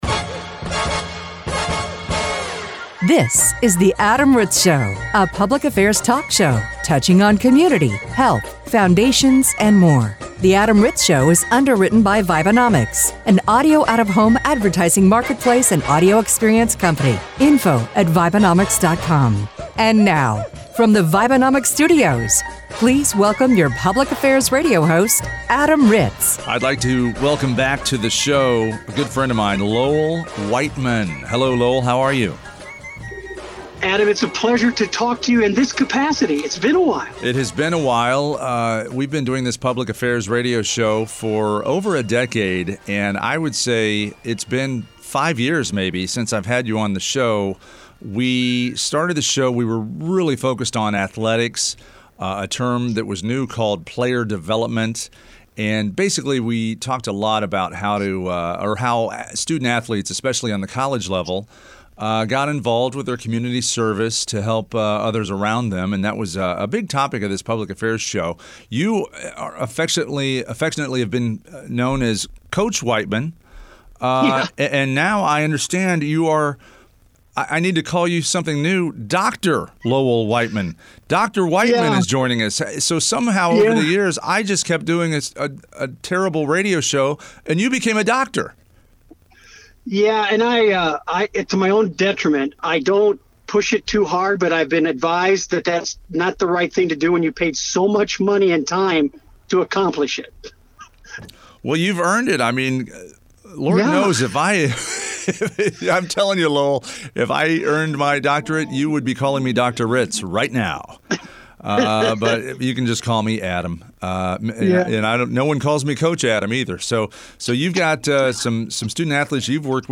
This show features an Interview